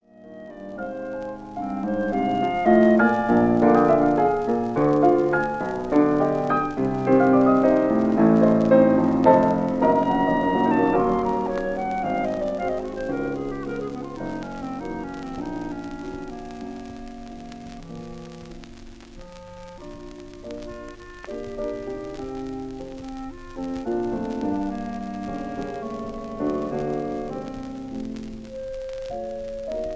Allegro amabile